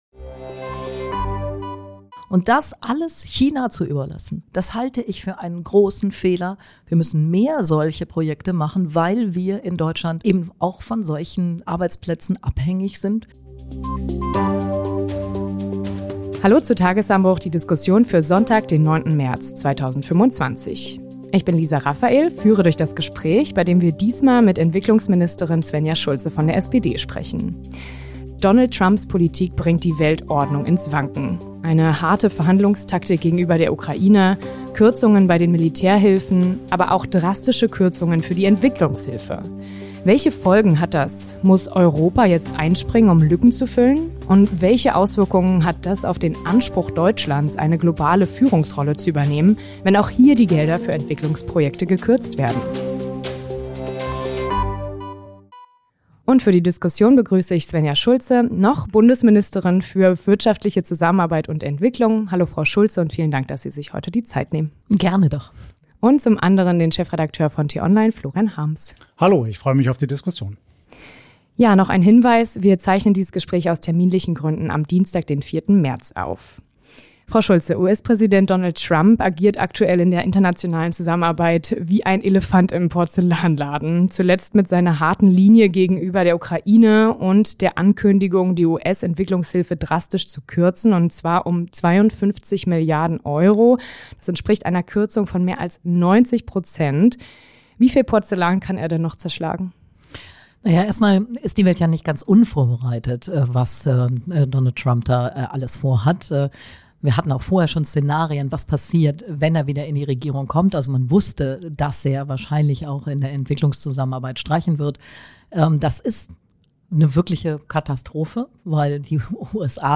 Globale Krisen, schrumpfende Budgets und ein erstarktes China – kann sich Deutschland Entwicklungshilfe noch leisten? Svenja Schulze, Bundesministerin für wirtschaftliche Zusammenarbeit und Entwicklung, erklärt, warum Kürzungen jetzt der falsche Weg wären und wo Deutschlands Rolle in der Welt in Zukunft sein wird.